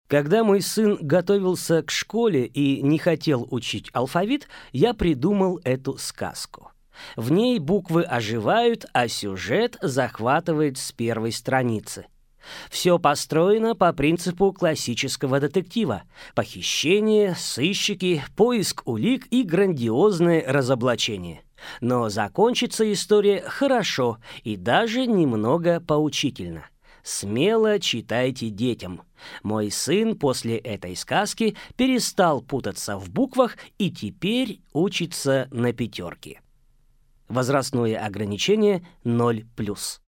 Аудиокнига Кто ограбил букву А? Детектив для самых маленьких | Библиотека аудиокниг